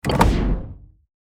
Racing, Driving, Game Menu, Ui Clear Sound Effect Download | Gfx Sounds
Racing-driving-game-menu-ui-clear.mp3